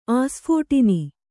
♪ āsphōṭini